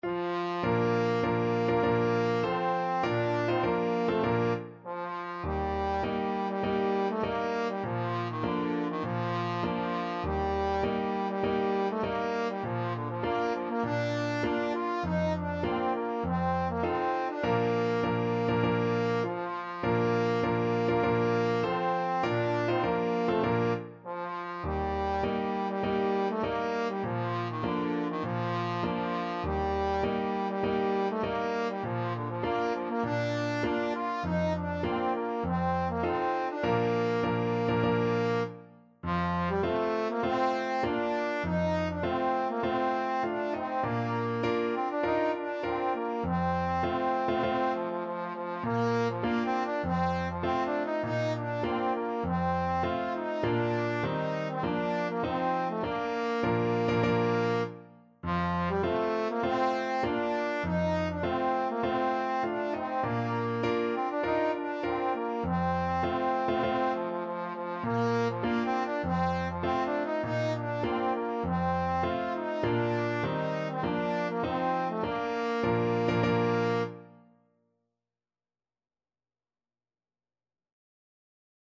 4/4 (View more 4/4 Music)
Classical (View more Classical Trombone Music)